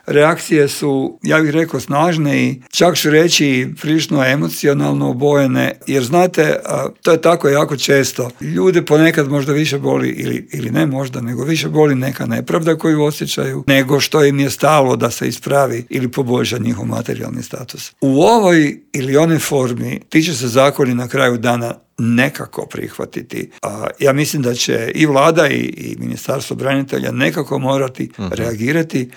Saborski zastupnik iz redova platforme Možemo! Damir Bakić u Intervjuu Media servisa poručio je da će se povećanje cijena goriva preliti i na druga poskupljenja: "Teret ove krize podnijet će građani i umirovljenici".